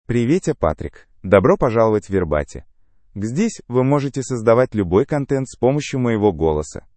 MaleRussian (Russia)
Patrick — Male Russian AI voice
Patrick is a male AI voice for Russian (Russia).
Voice sample
Male